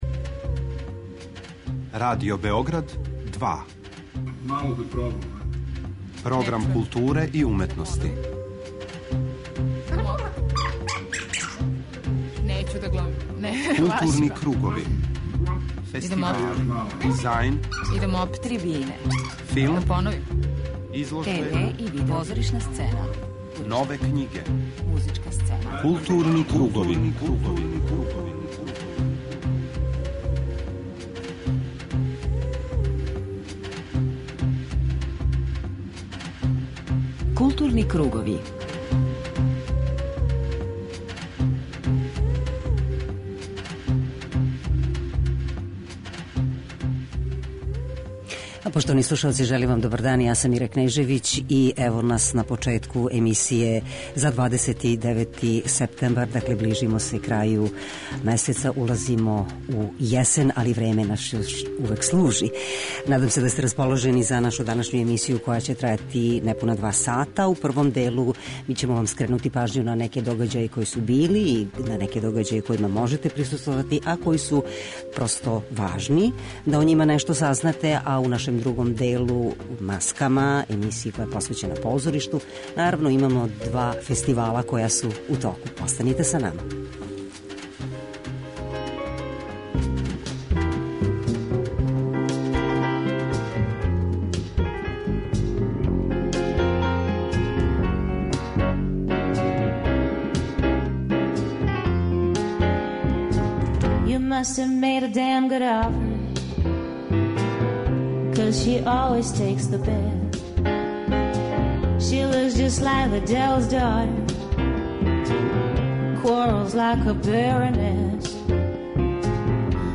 У тематском делу Маске, чућете разговор са позоришним критичарима, учесницима 28. Међународног конгреса позоришних критичара, који се одржава у Београду у оквиру 50. Битефа.